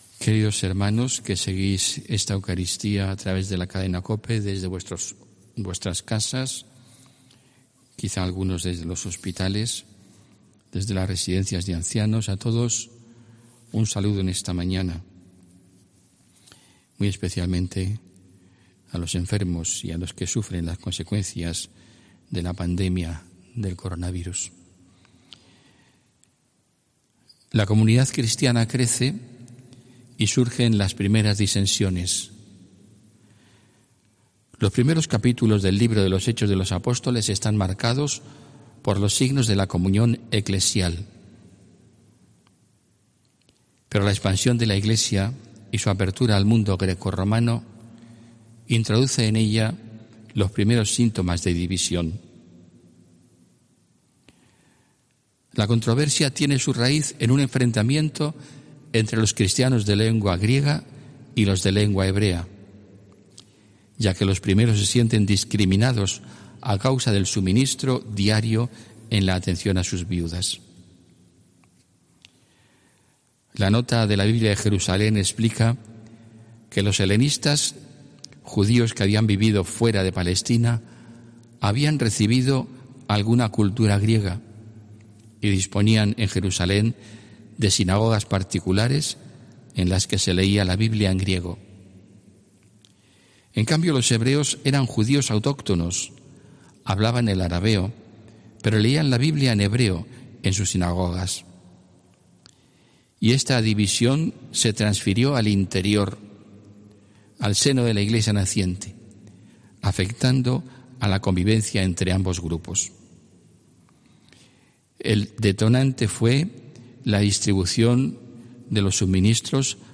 HOMILÍA 10 MAYO 2020